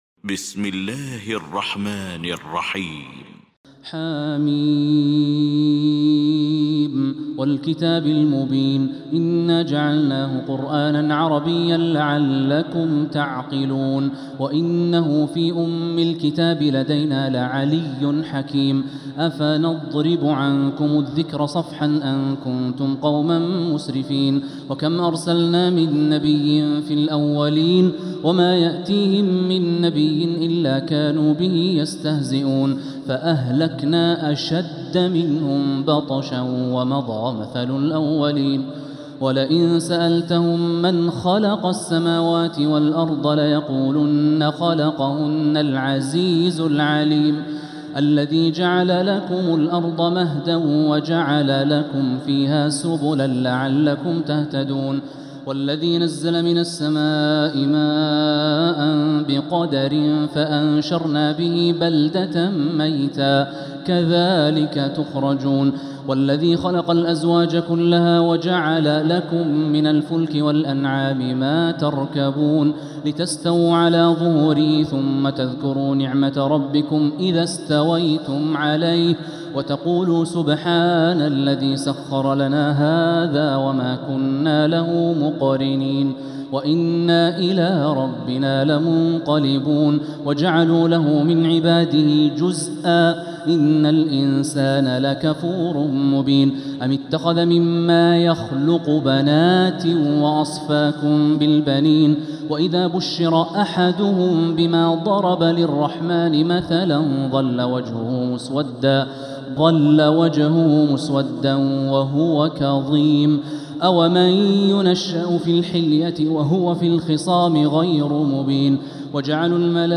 سورة الزخرف Surat Az-Zukhruf > مصحف تراويح الحرم المكي عام 1446هـ > المصحف - تلاوات الحرمين